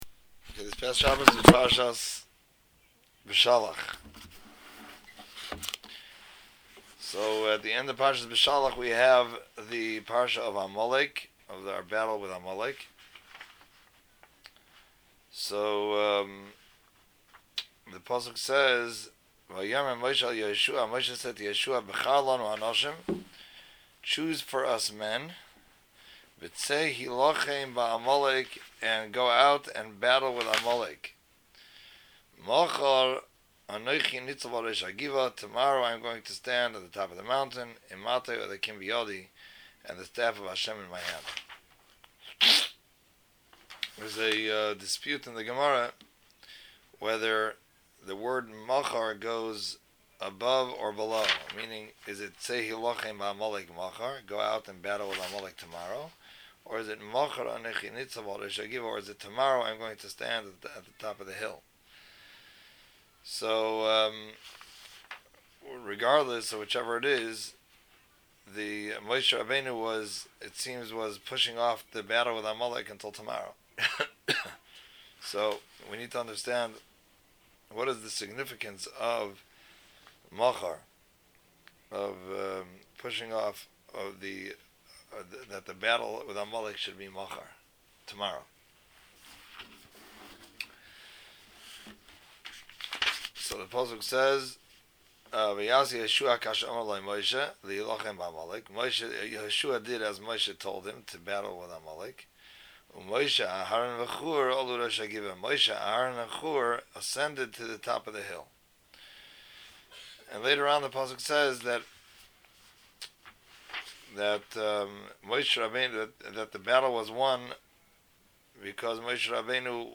Beshalach Drasha- Miriam, Chana and Rachel